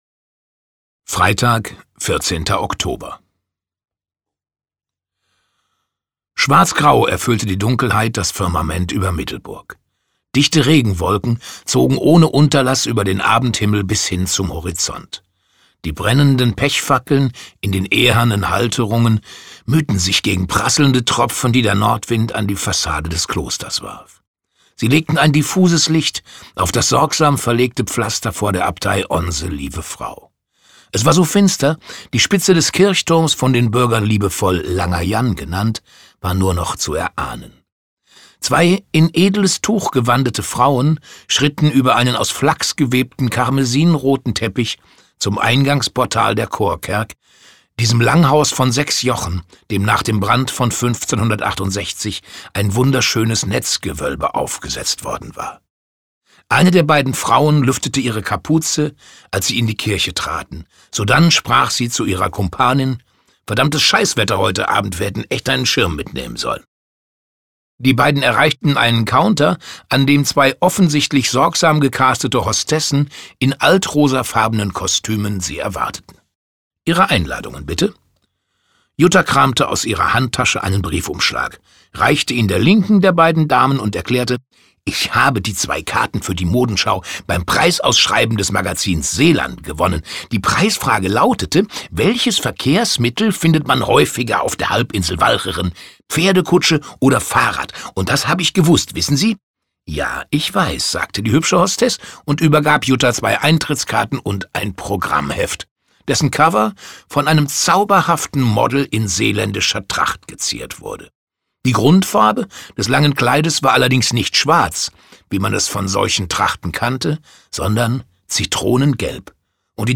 Mode, Mord und Meeresrauschen Camping-Krimi. Bernd Stelter (Autor) Bernd Stelter (Sprecher) Audio-CD 2024 | 1.